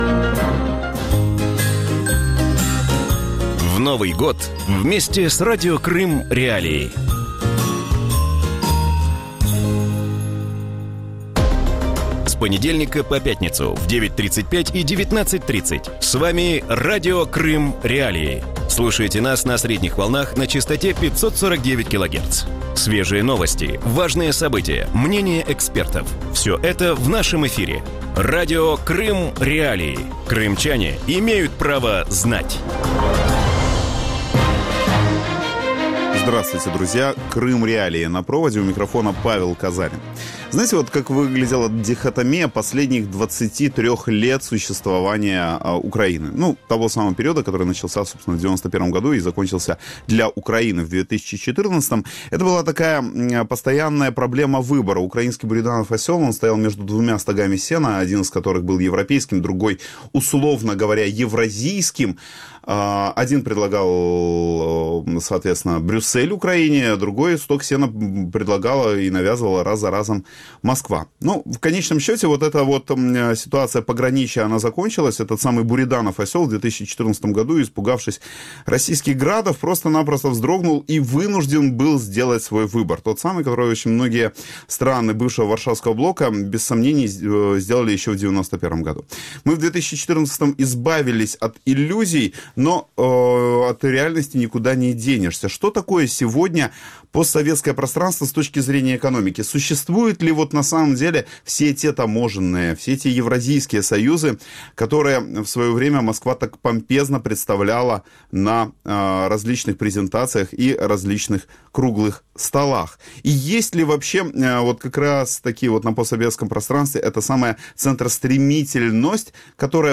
В вечернем эфире Радио Крым.Реалии обсуждают перспективы Евразийского экономического союза. ЕАЭС – это политический или экономический проект, почему его участники не стремятся начинать экономическую интеграцию с Россией и кто выигрывает от его реализации?